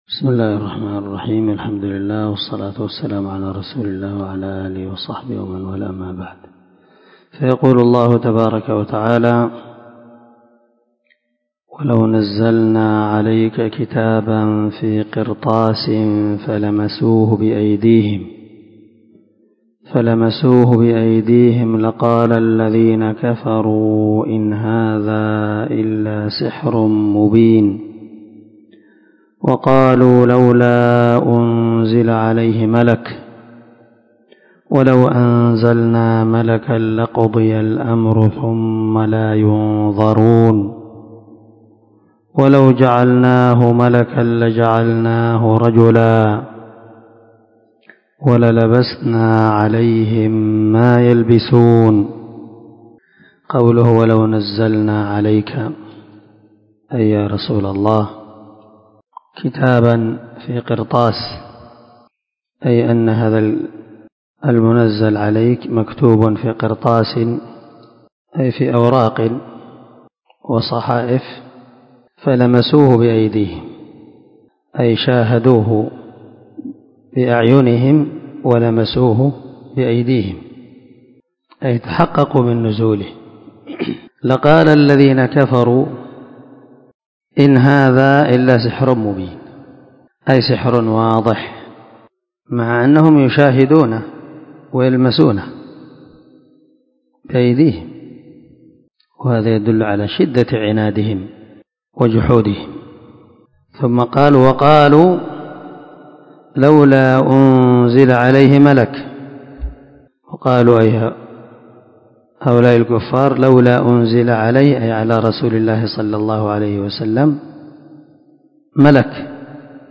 395الدرس 3 تفسير آية ( 7 - 9 ) من سورة الأنعام من تفسير القران الكريم مع قراءة لتفسير السعدي